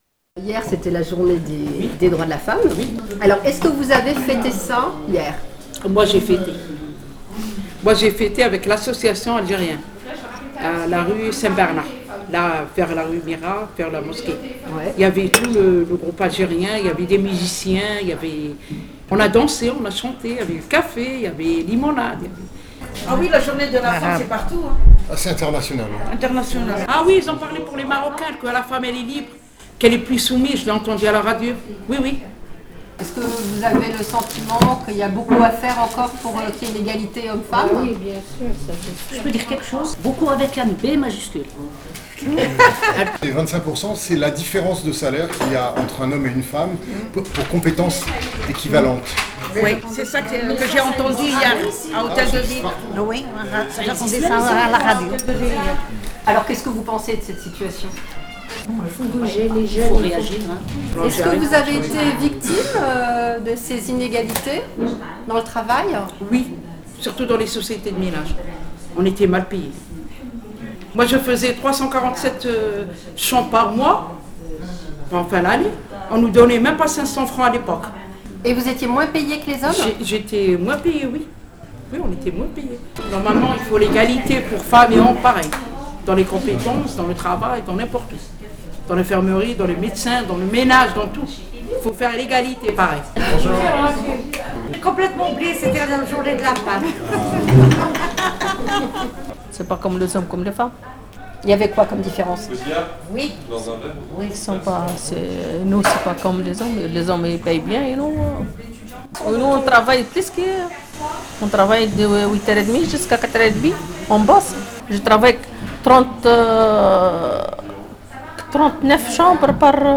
[Radio Café Social] Journée du droit des femmes : "C’est quoi la liberté ? L’indépendance avant tout"